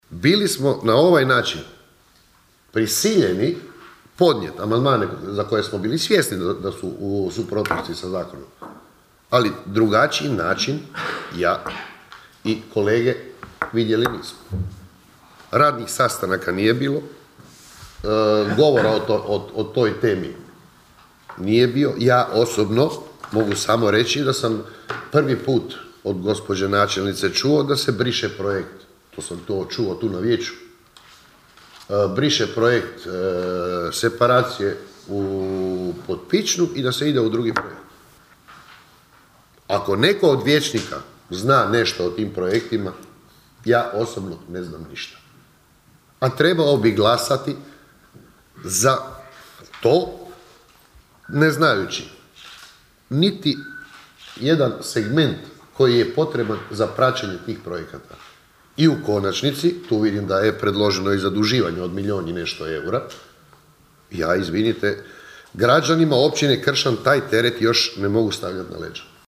Sjednica Općina Kršan
Zašto su podnijeli te amandmane pojasnio je vijećnik SDP-a Silvano Uravić: (